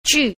4. 聚 – jù – tụ